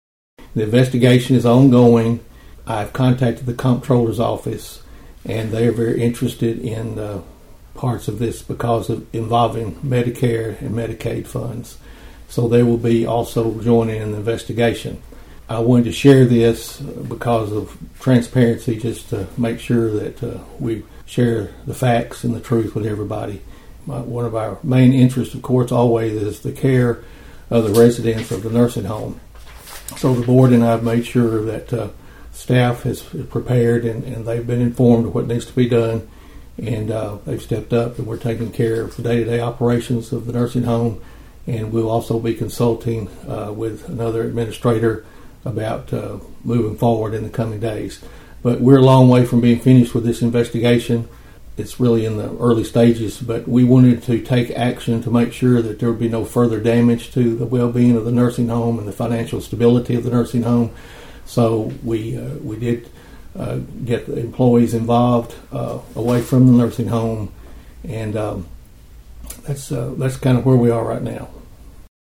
Mayor Carr explained the investigation into the discovery of the missing funds, and the ongoing operations at the nursing home.(AUDIO)